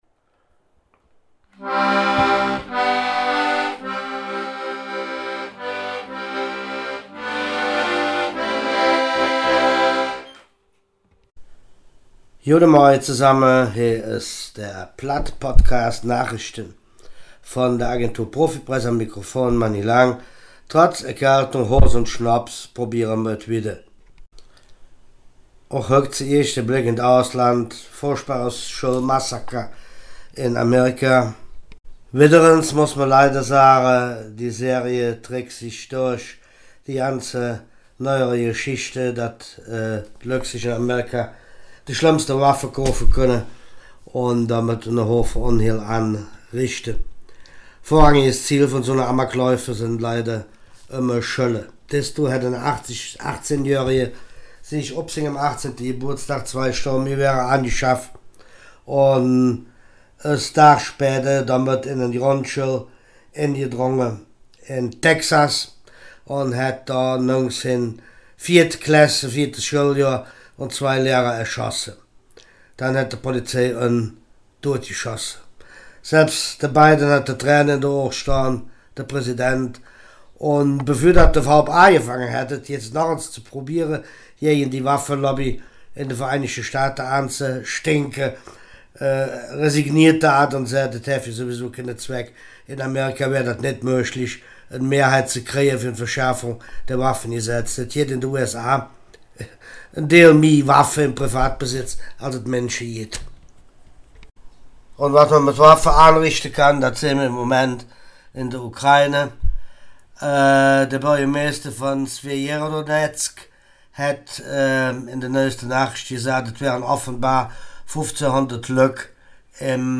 Nachrichten vom 27. Mai
Podcast op Platt Nachrichten vom 27.